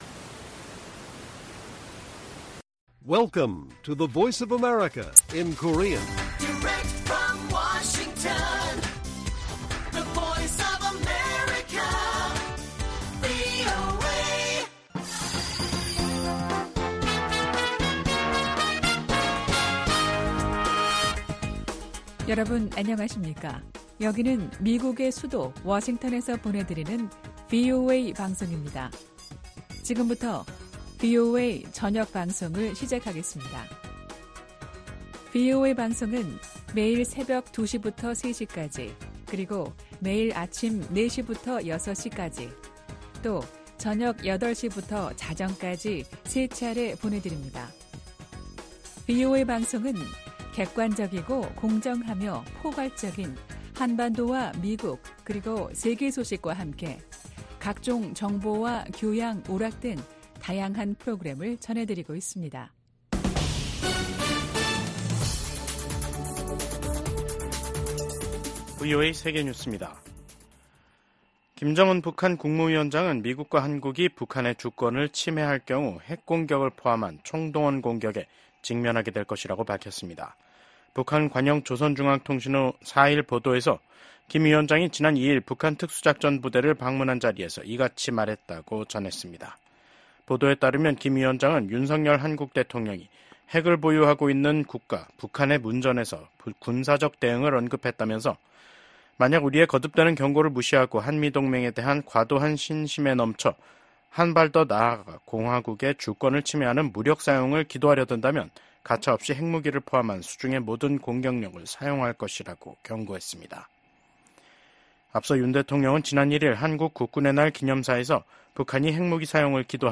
VOA 한국어 간판 뉴스 프로그램 '뉴스 투데이', 2024년 10월 4일 1부 방송입니다. 이시바 시게루 신임 일본 총리가 제안한 ‘아시아판 나토’ 구상에 대해 미국 하원의원들은 대체로 신중한 반응을 보였습니다. 김정은 북한 국무위원장은 윤석열 한국 대통령을 실명으로 비난하면서 미국과 한국이 북한 주권을 침해하려 할 경우 핵무기로 공격하겠다고 위협했습니다.